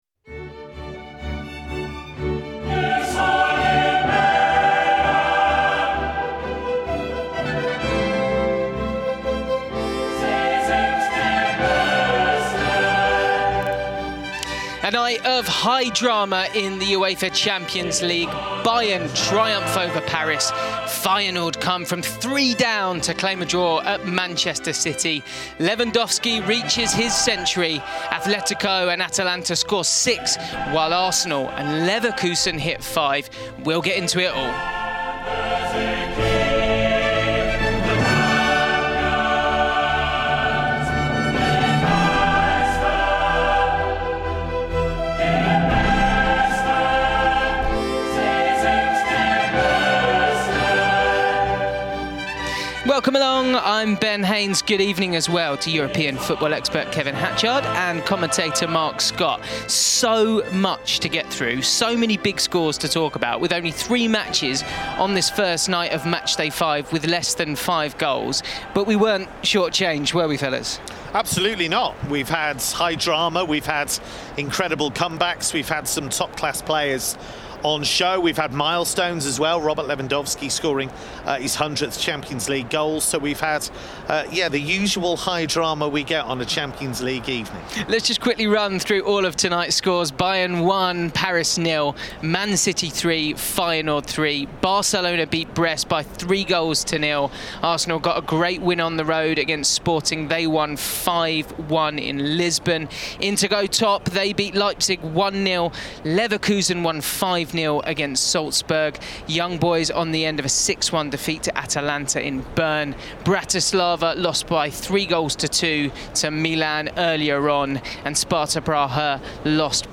We hear from Feyenoord’s Brian Priske, Arsenal’s Bukayo Saka, plus Tammy Abraham reacts to AC Milan’s 3-2 victory at Bratislava.